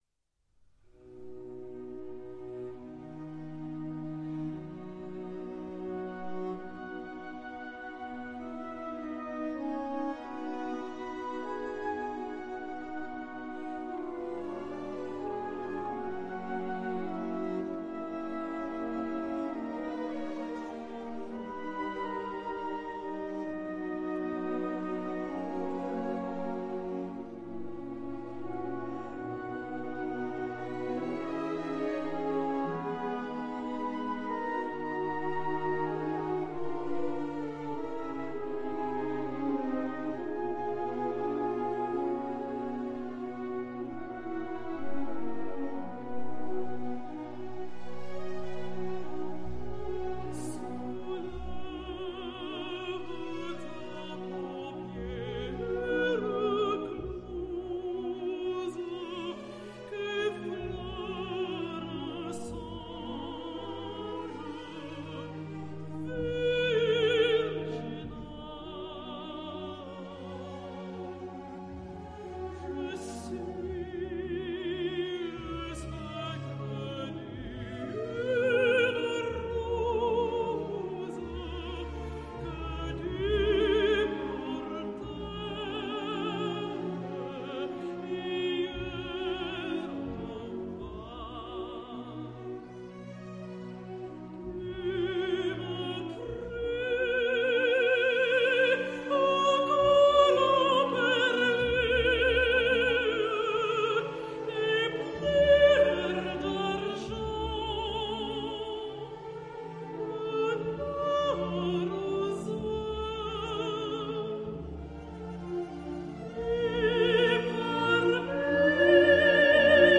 Je ne résiste pas au plaisir de vous livrer une autre version de cet air magnifique, celle d’Yvonne Minton avec l’orchestre de la BBC dirigé par Pierre Boulez. À partir de 4’01 », la cantatrice va entamer un crescendo bouleversant jusqu’à « J‘arrive du paradis«  qui m’émeut à chaque fois :